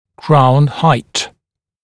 [kraun haɪt][краун хайт]высота коронки